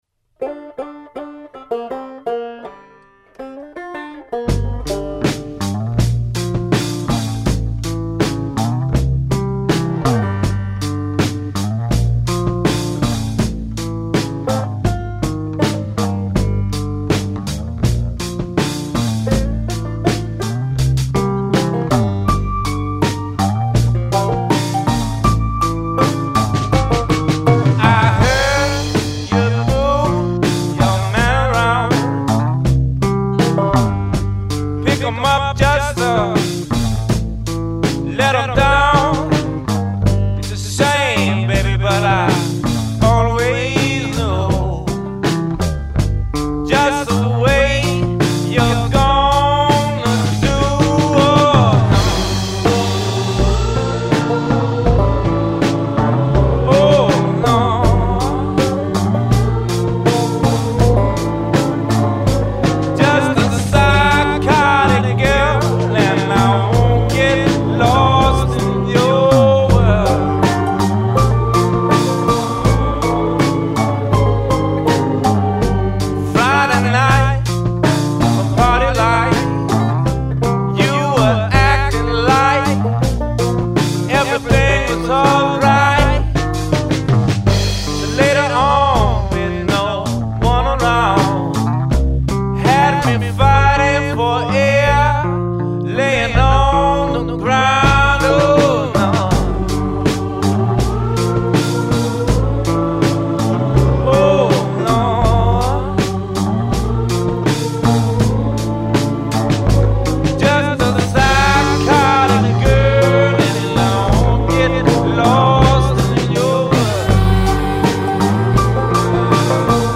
Funky folk? Blues rock?